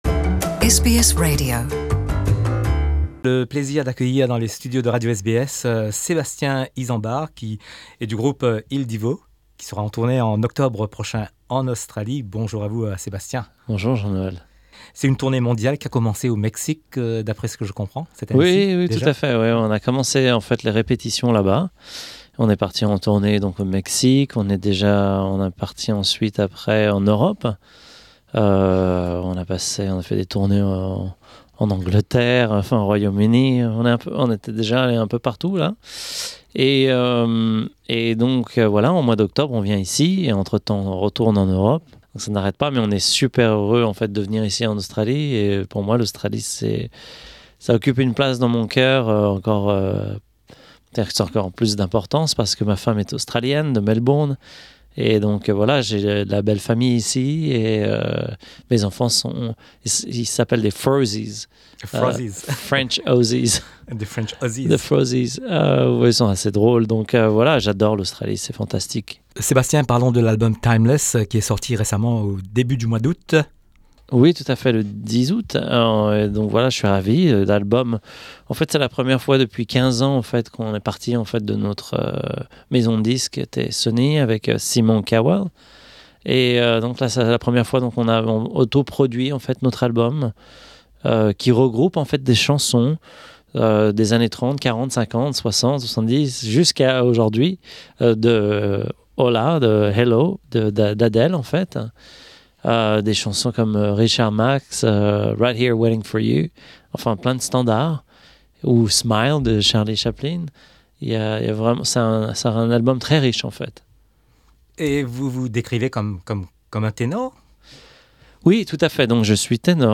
From August 23 to 27, the classical music group Il Divo came to Australia to prepare their new album and their next tour. SBS French met Sébastien Izambard, the French singer from the quartet.